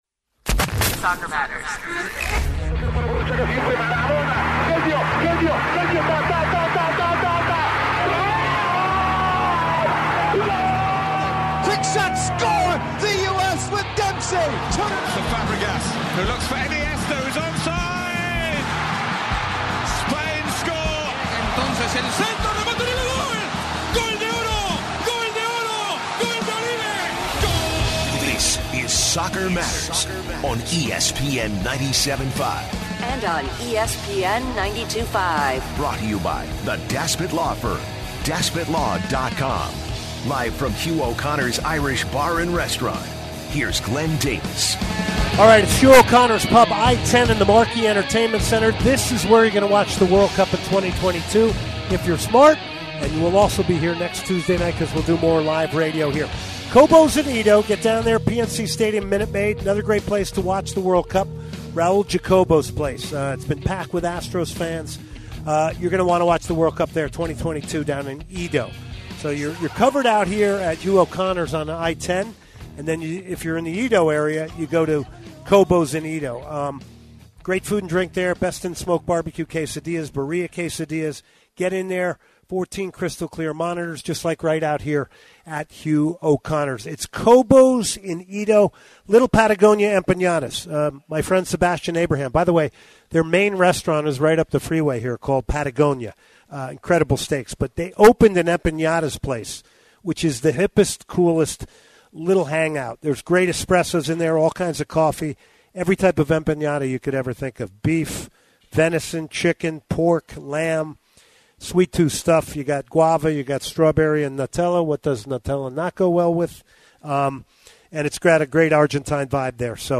Live from Hugh O'Connors Pub